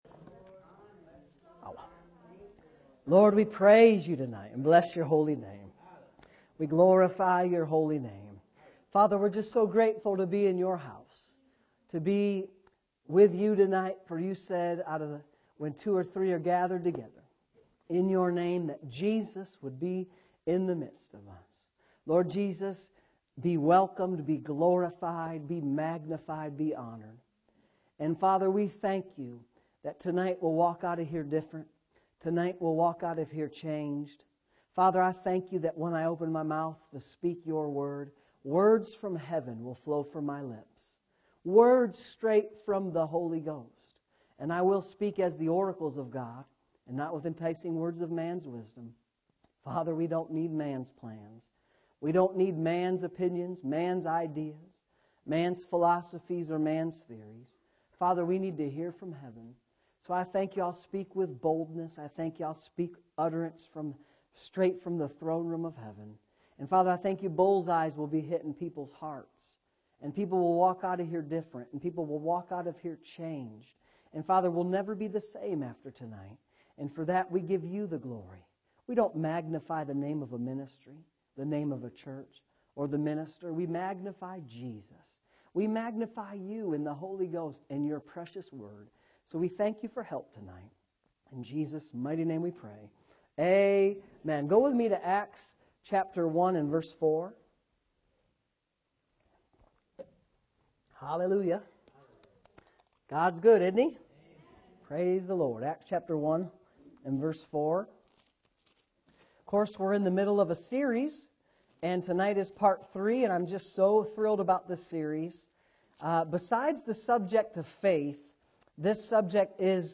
Saturday Evening Services